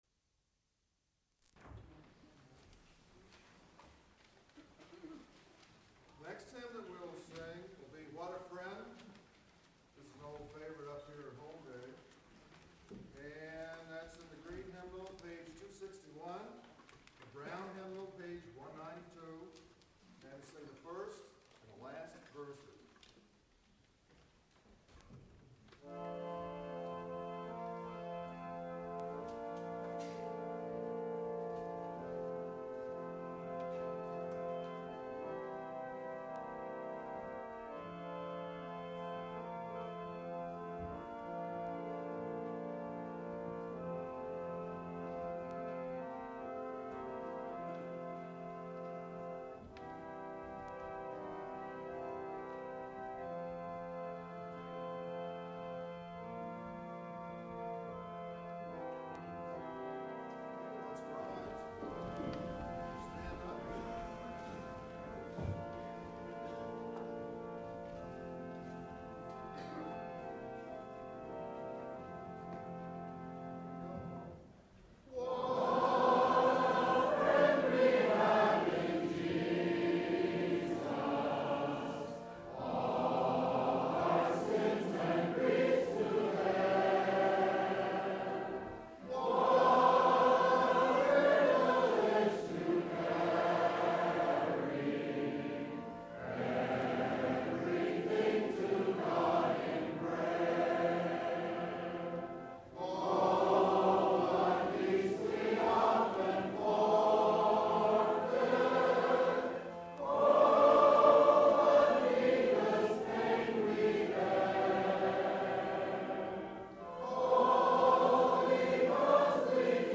for Woodwind Quintet (1988)